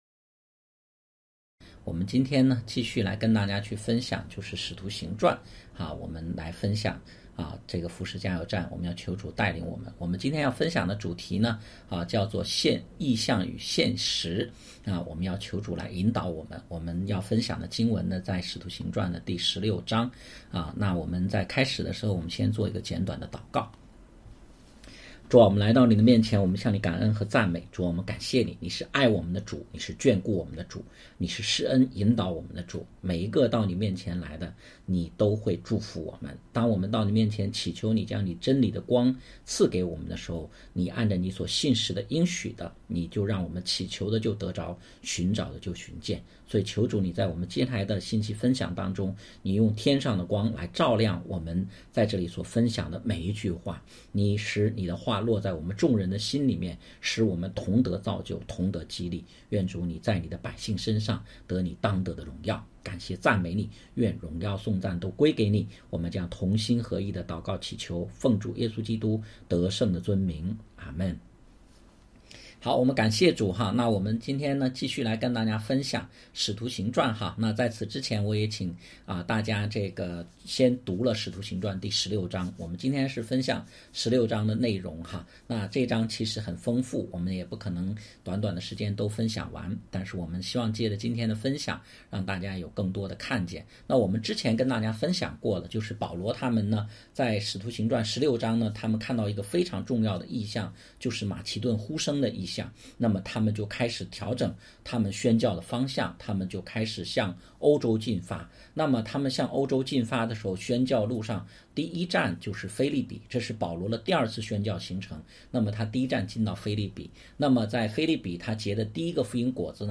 讲道录音 点击音频媒体前面的小三角“►”就可以播放 https